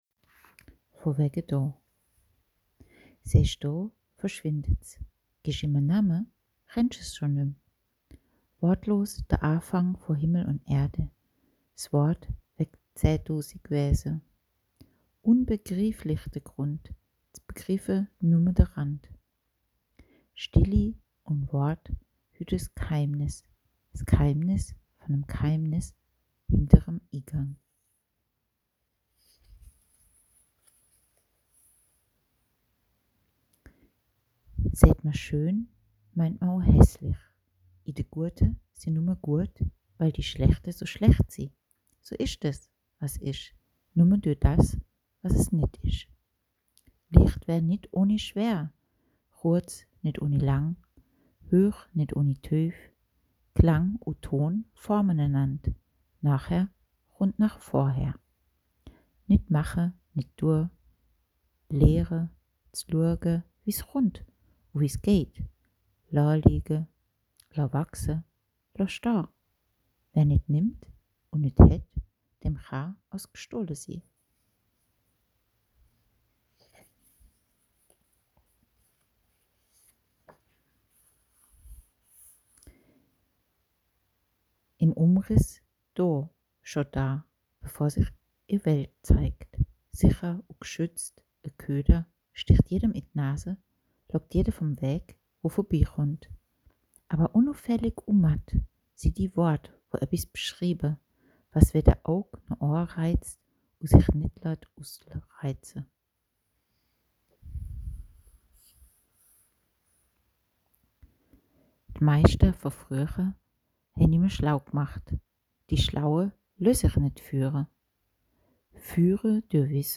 (Vor-)gelesen um zu verstehen: